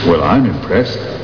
sound-impressed.wav